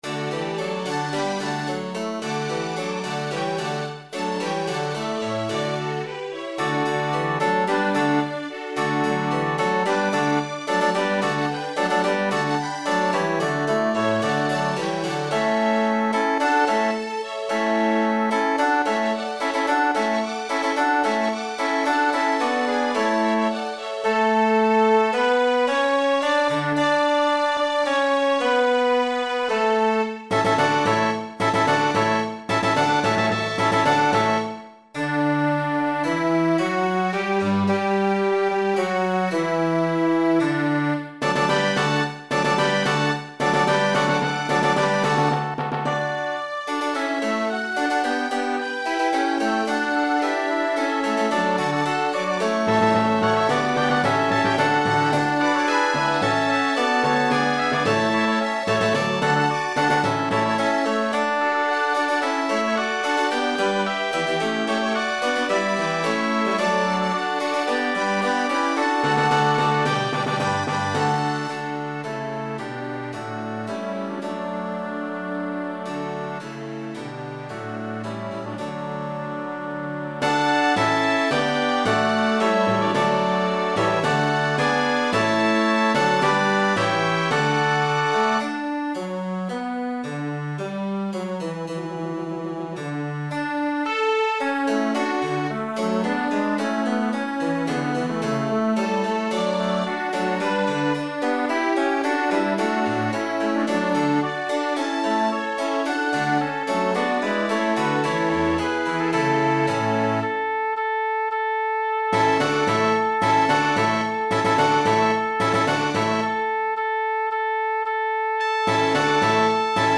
Tôi đang cần phần nhạc nền (hòa âm đầy đủ các nhạc cụ)của bài Alleluia (G.F.Handel) dưới dạng file mp3 (để download).